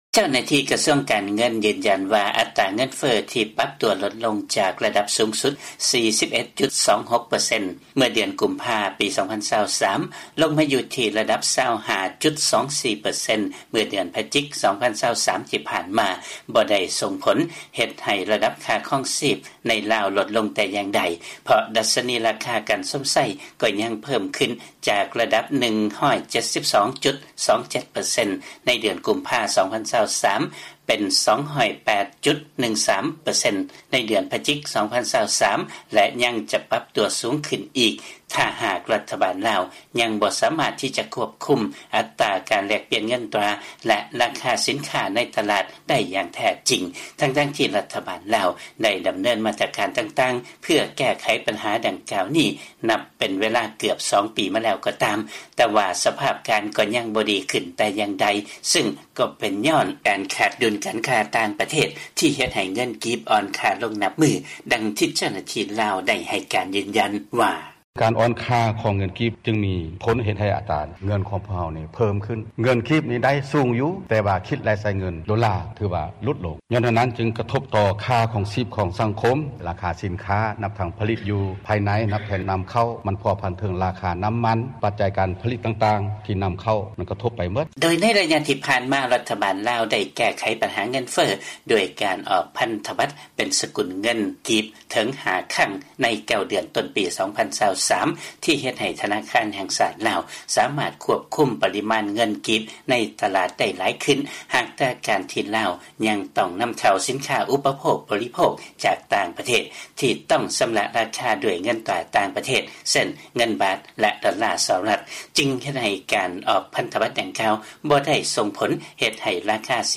ມີລາຍງານ ຈາກບາງ ກອກ.